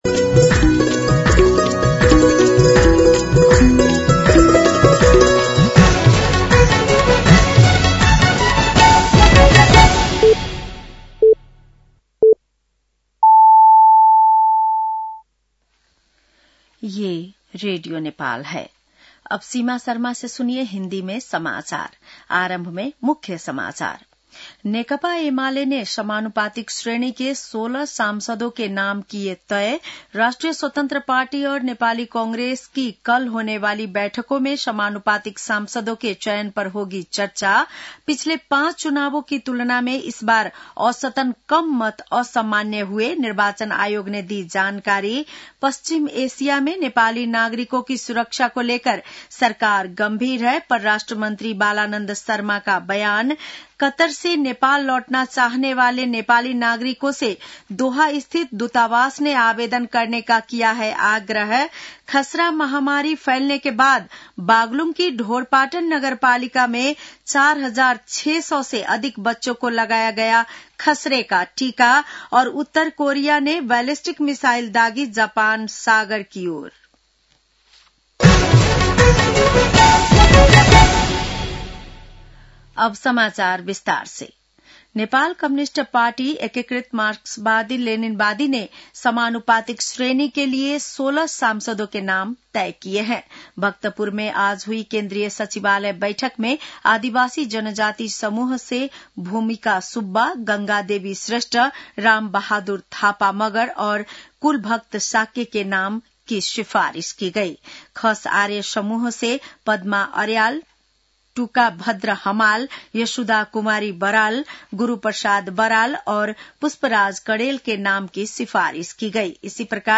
बेलुकी १० बजेको हिन्दी समाचार : ३० फागुन , २०८२
10-pm-news-.mp3